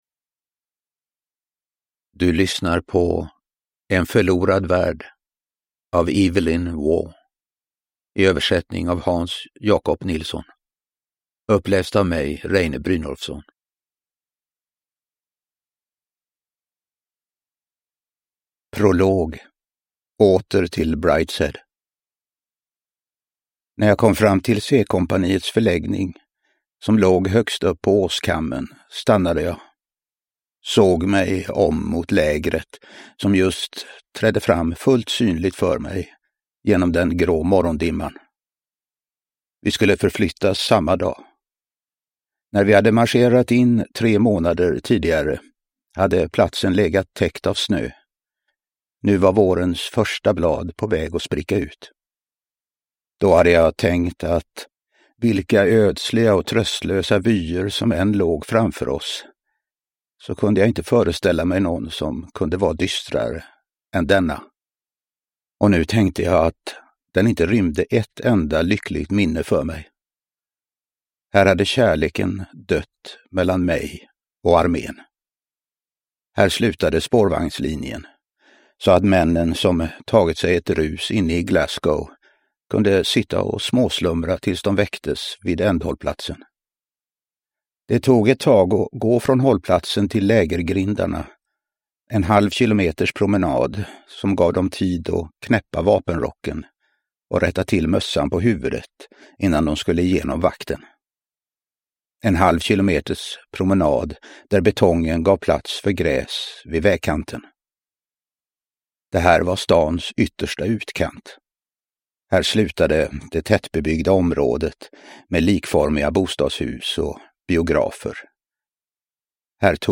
En förlorad värld – Ljudbok – Laddas ner
Uppläsare: Reine Brynolfsson